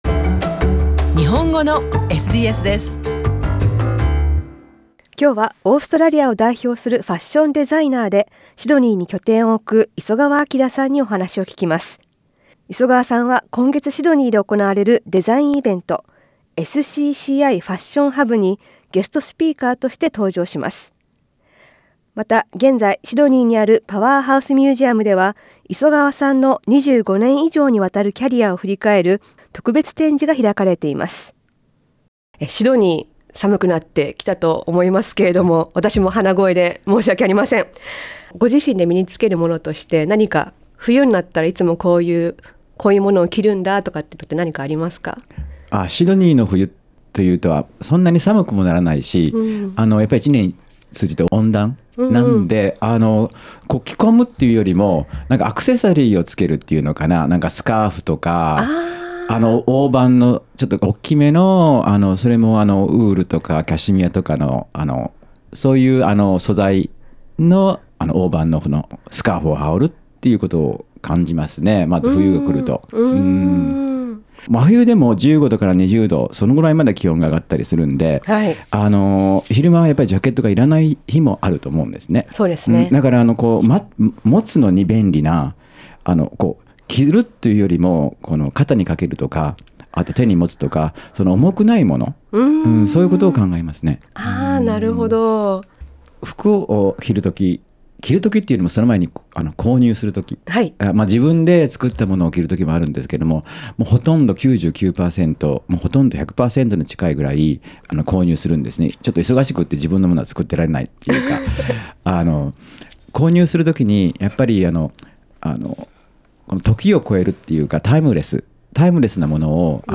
インタビューではイベントや日本文化から受けた影響などについて聞きました。